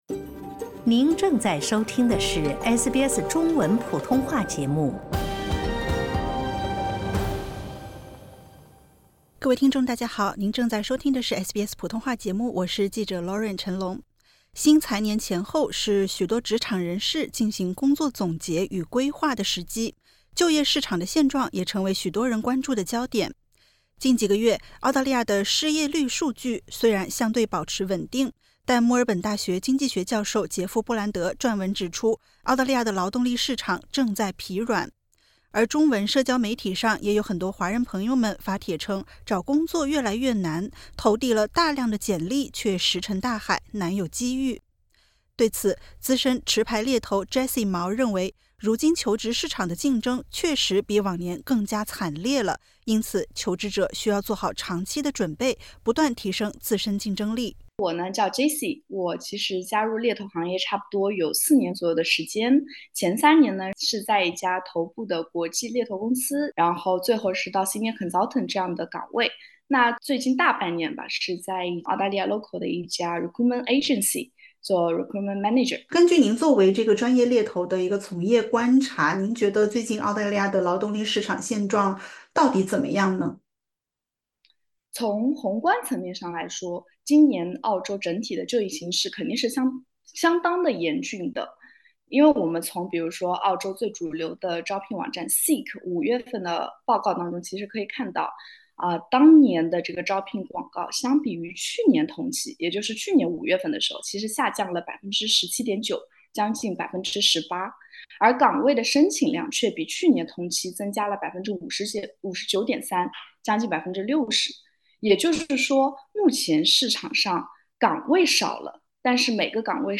面临如今现状，华人求职如何提升竞争力？点击 ▶ 收听完整采访。